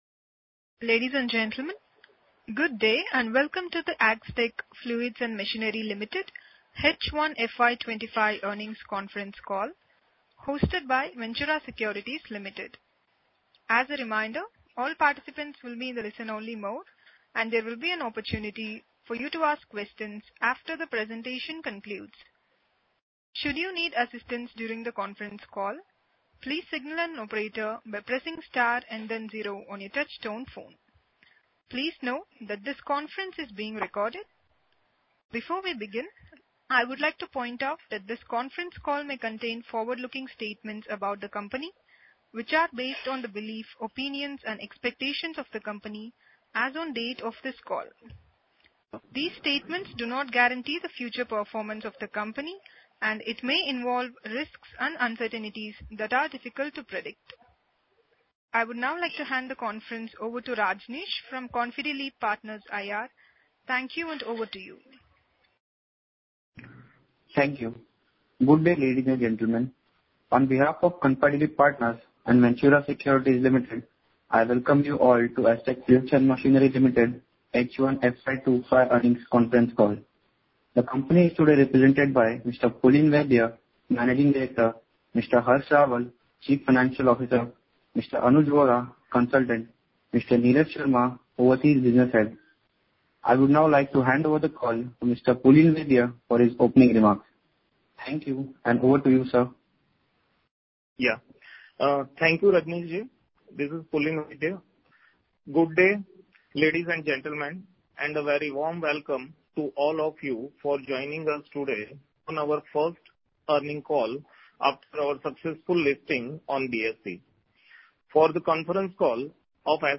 Concalls
H1-FY25-concall-audio-recording.mp3